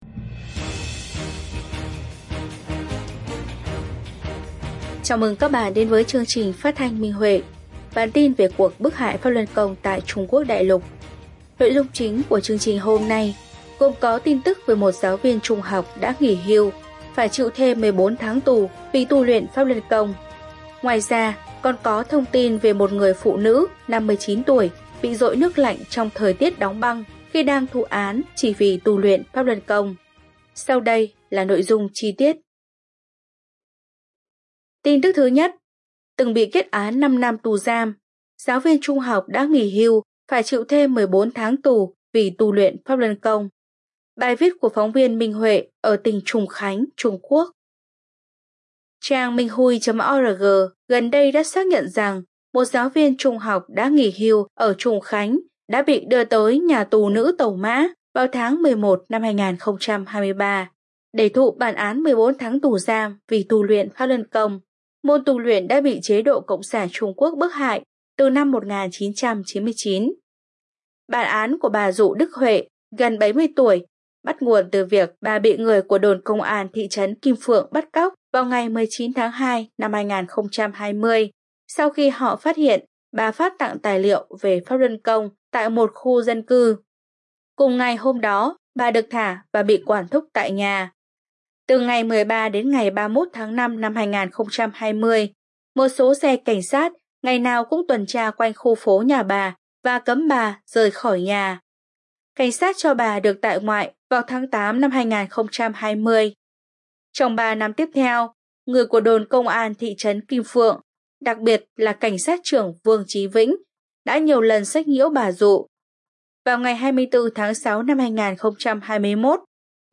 Chương trình phát thanh số 78: Tin tức Pháp Luân Đại Pháp tại Đại Lục – Ngày 20/02/2024